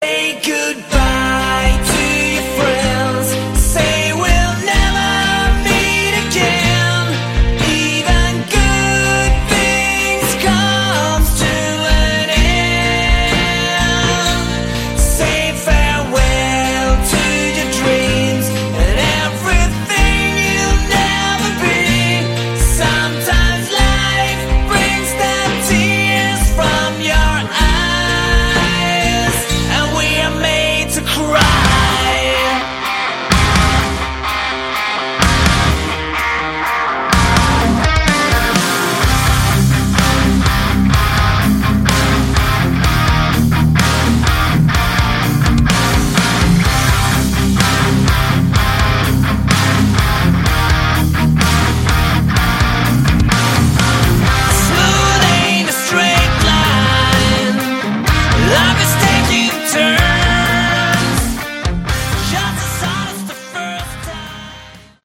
Category: Hard Rock
vocals, guitar
drums